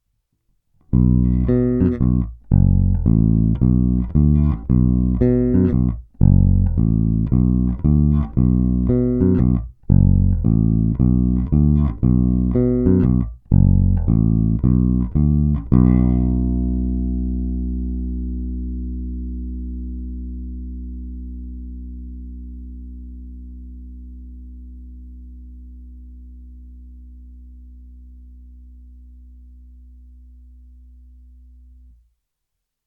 Zvuk je konkrétní, pevný, hutný, vrčivý, strašlivě tlačí, prostě naprosto ultimátní Jazz Bass.
Ukázky jsou nahrány rovnou do zvukovky, jednotlivé nahrávky jsou normalizovány. Není-li uvedeno jinak, tónová clona je plně otevřená. Použité struny jsou neznámé ocelovky, pětačtyřicítky, ohrané, ale ještě v poměrně slušném stavu.
Snímač u krku